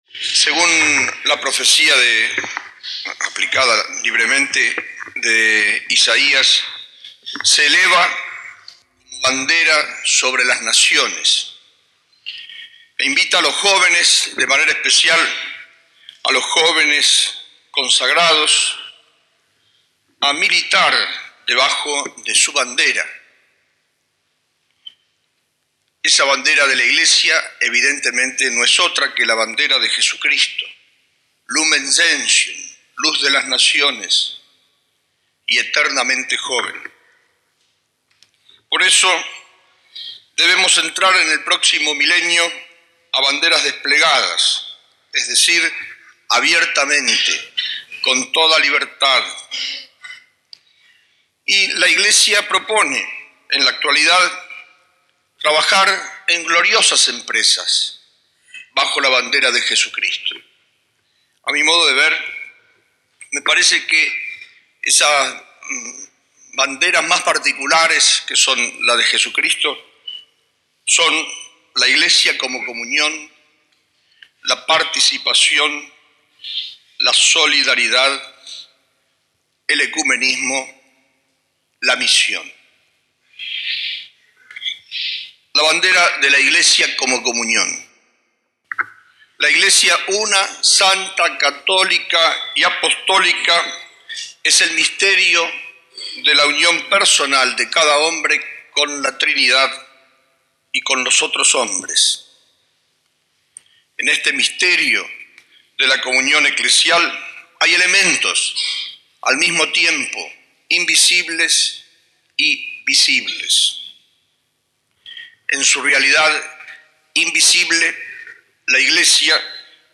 Sermon-La-Bandera-de-Jesucristo-1996.mp3